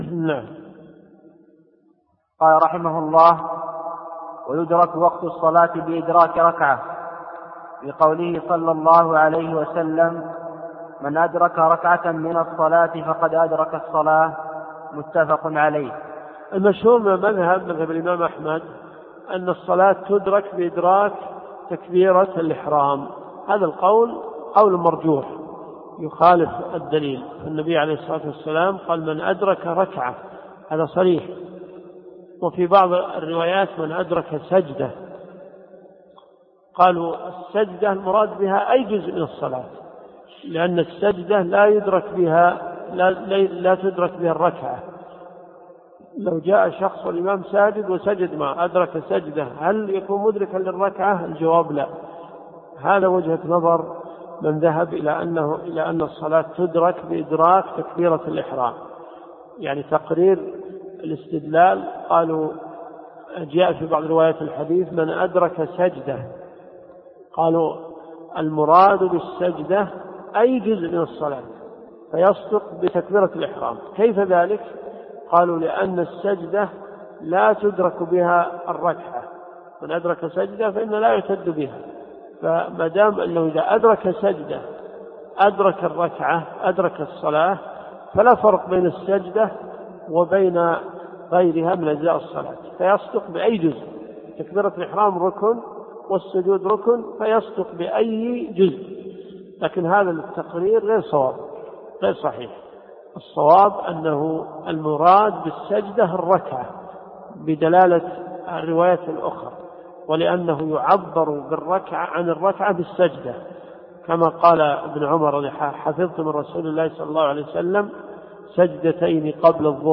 دروس صوتيه ومرئية تقام في جامع الحمدان بالرياض - فتاوى .
مقطوع مأخوذ من شرح آخر ليجبر السقط